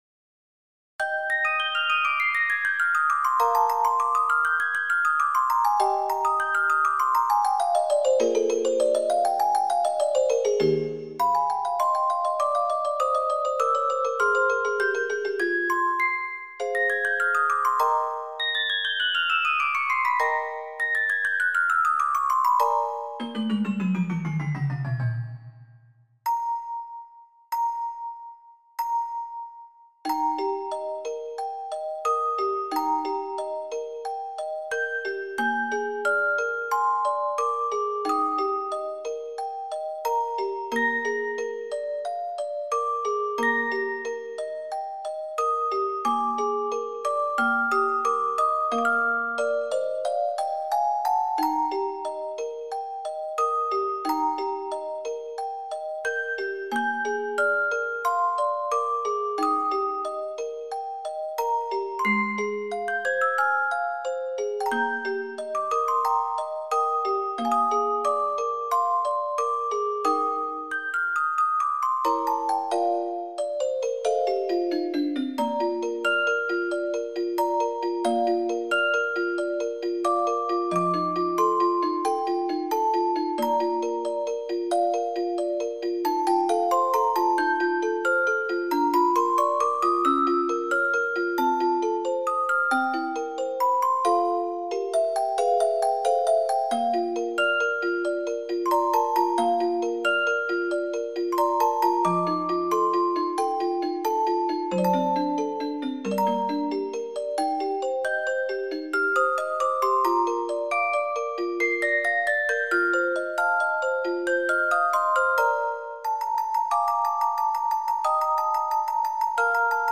クラシック曲（作曲家別）－MP3オルゴール音楽素材
オルゴール チェレスタ ミュージックボックス